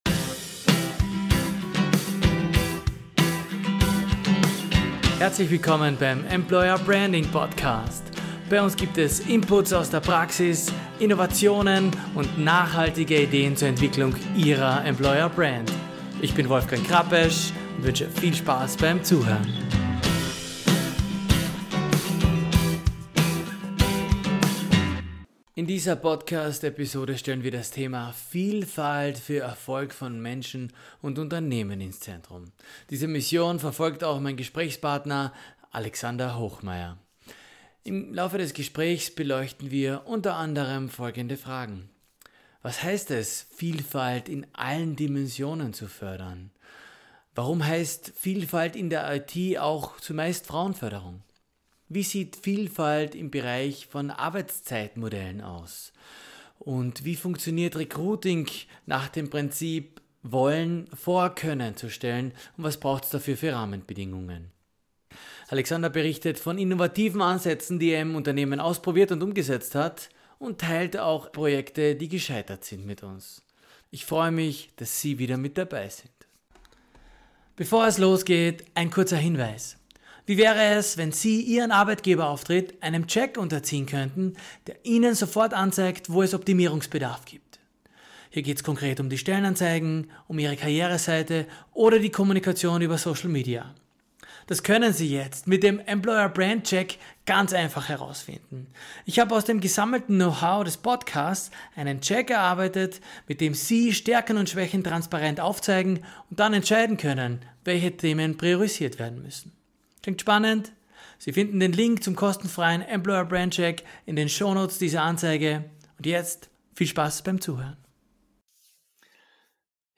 Erfolgsfaktor Vielfalt - Gespräch